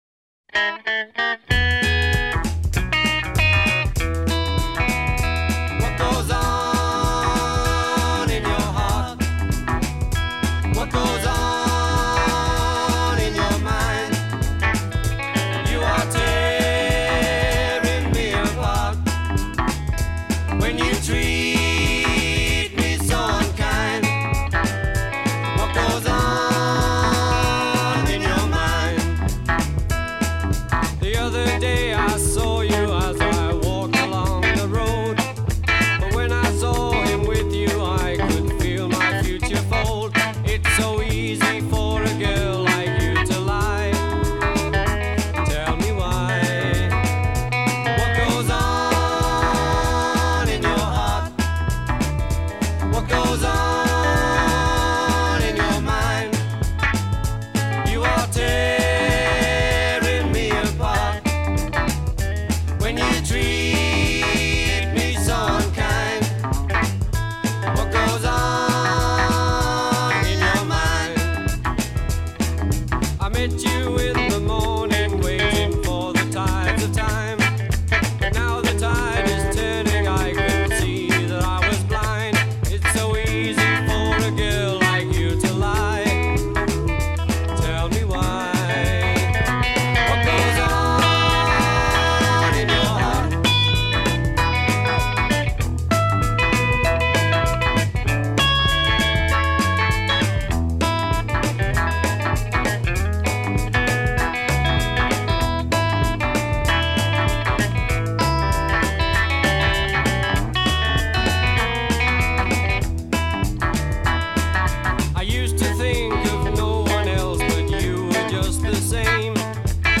innocuous, out-of-tune delivery